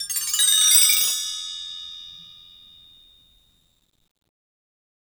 BellTree_Stroke2_v1_Sum.wav